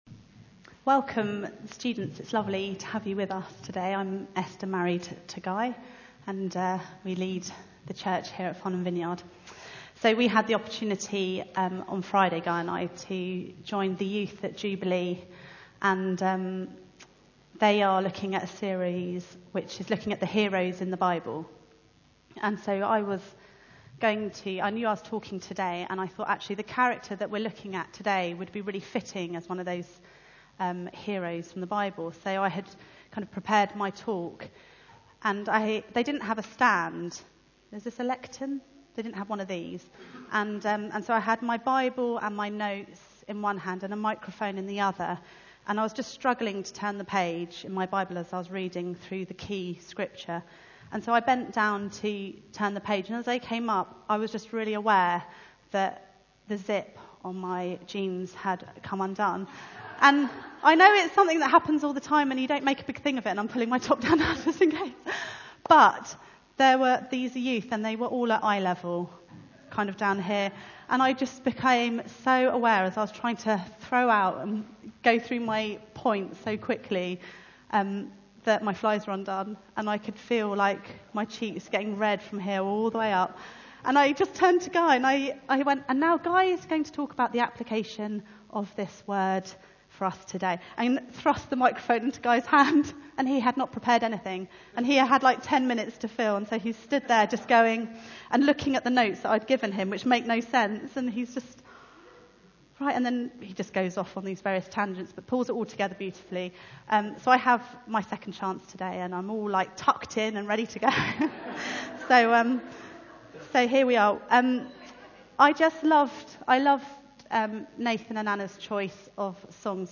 Passage: Luke 7:36-50 Service Type: Sunday Meeting